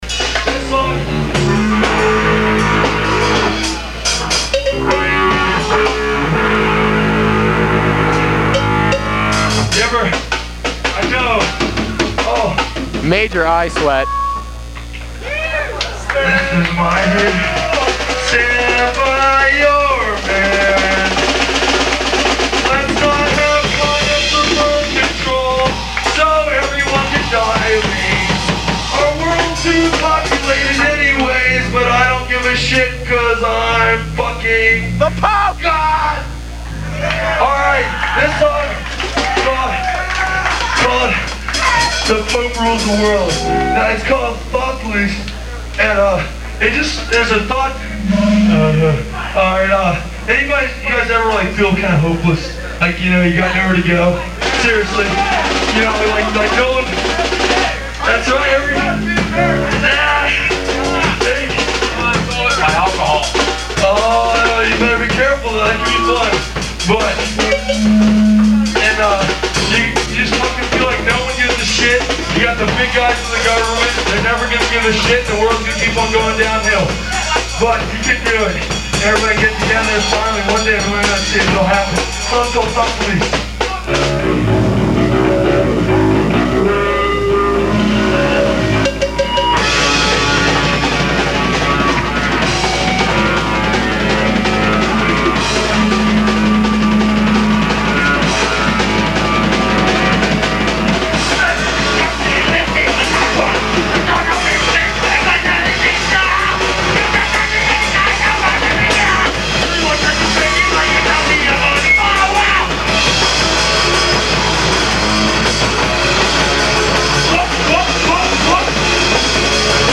side b – Upstage, Pittsburgh 12-07-91